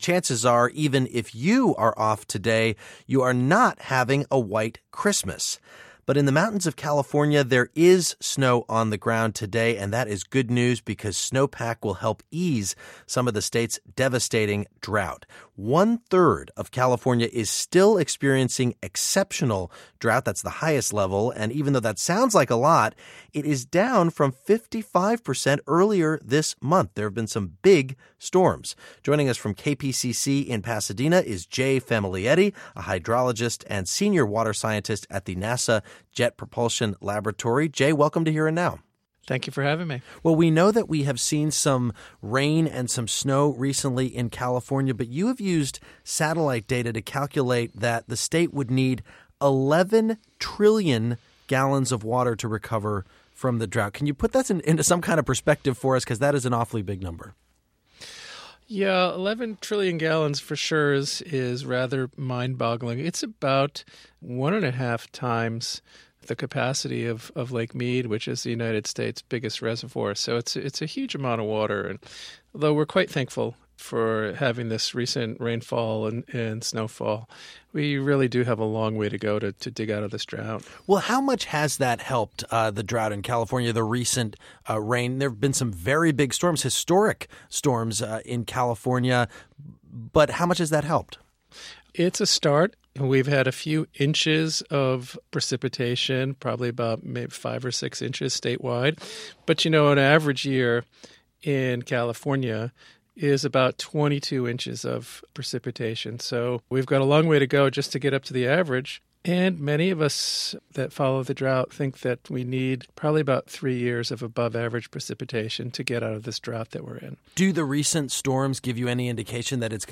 WNYC Podcast- Hydrologist Talks About New Rains Helping California Drought